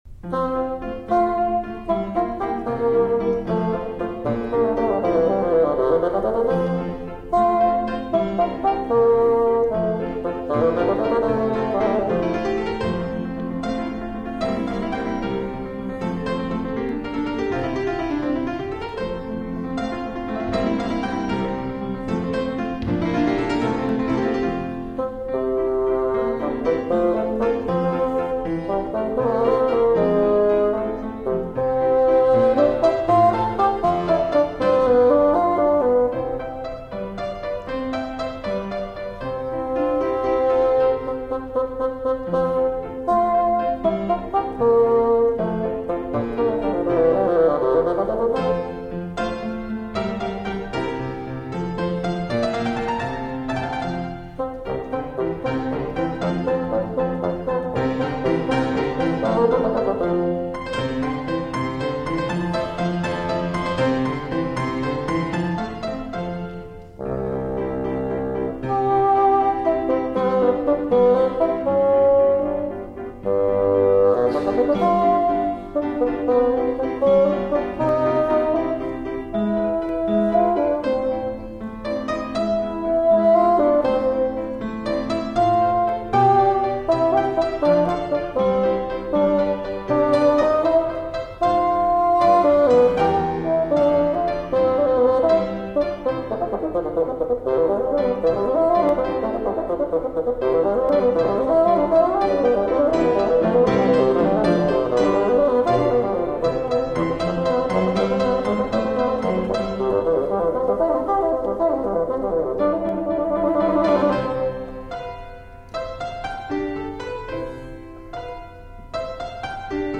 bassoon
fortepiano Oct. 1997 Kent University